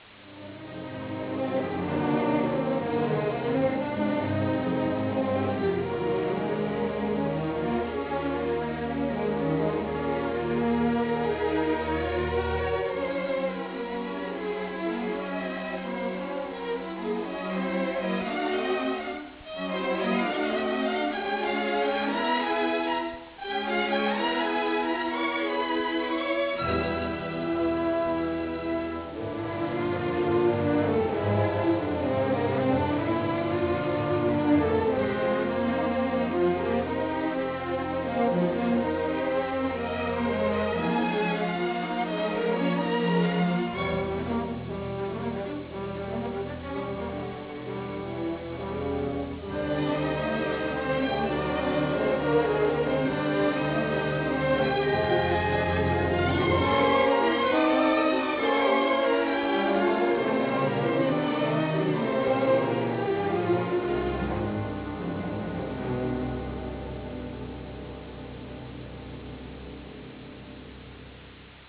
Musica in stile medioevale
Original track music